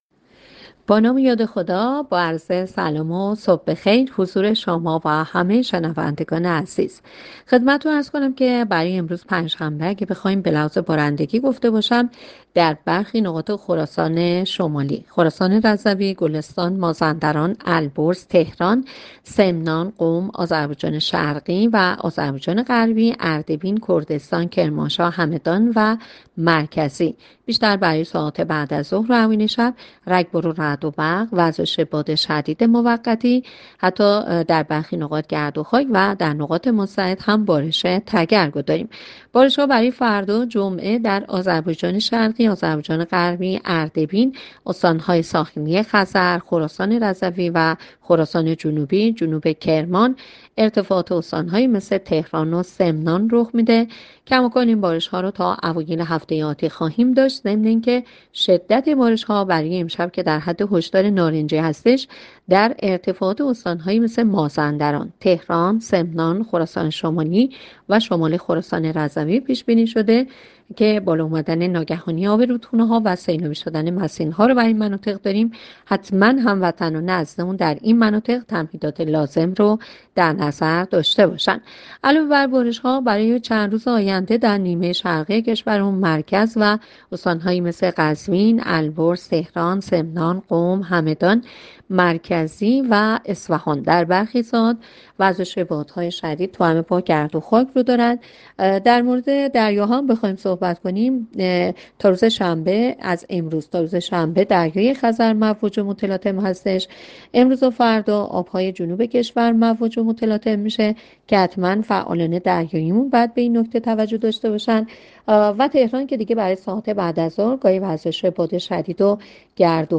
گزارش رادیو اینترنتی پایگاه‌ خبری از آخرین وضعیت آب‌وهوای ۱۵ خرداد؛